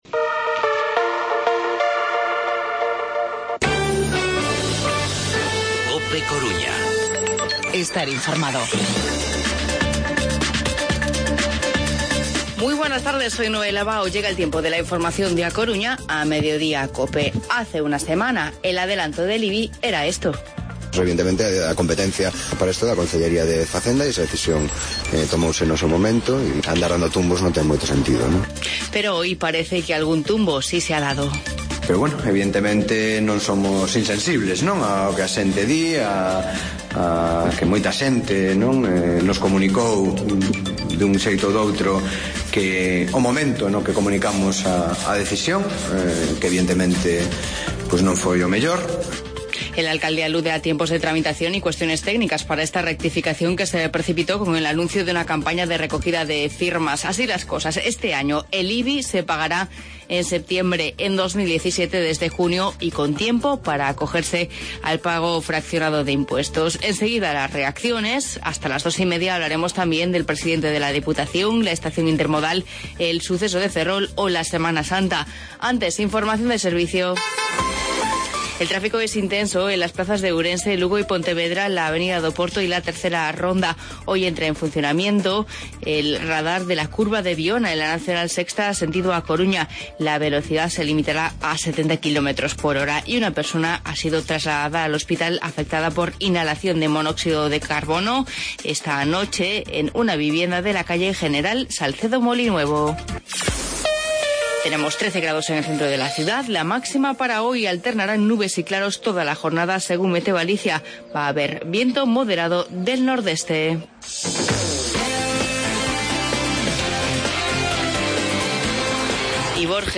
Informativo Mediodía COPE Coruña jueves, 17 de marzo de 2016